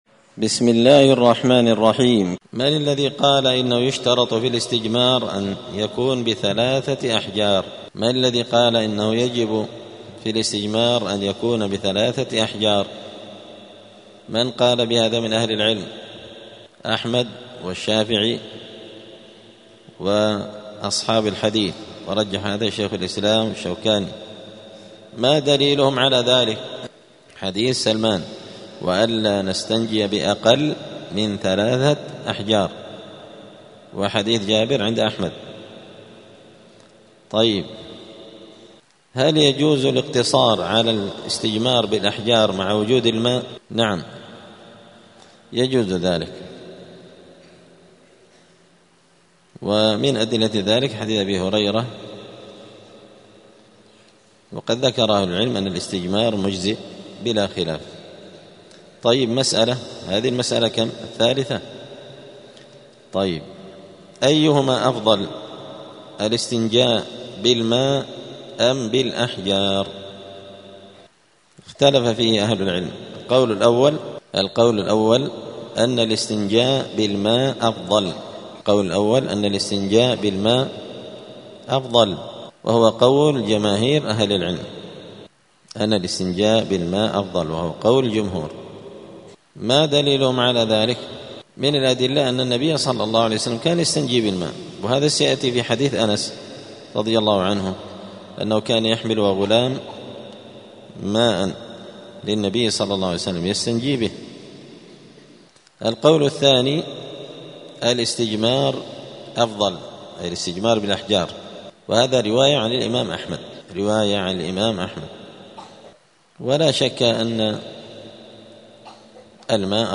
دار الحديث السلفية بمسجد الفرقان قشن المهرة اليمن
*الدرس السادس والستون [66] {باب الاستطابة حكم الجمع بين الحجارة والماء في الاستنجاء}*